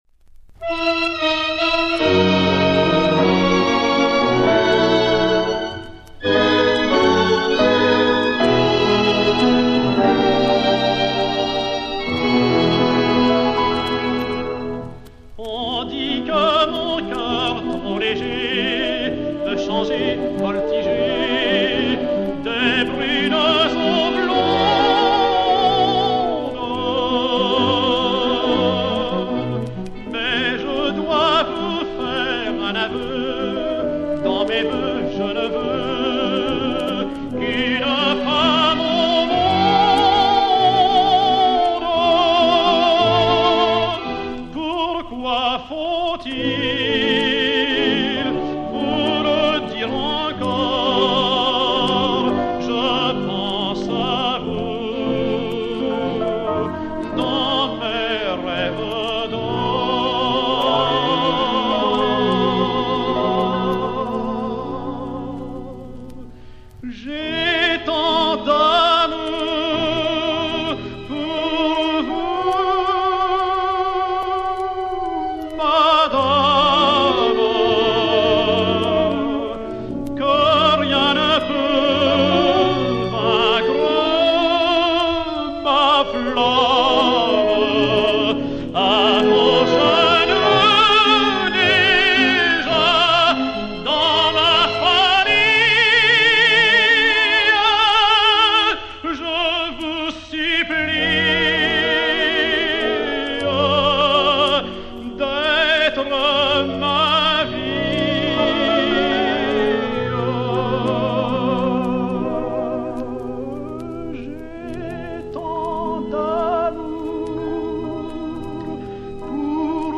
slow-fox
ténor de l'Opéra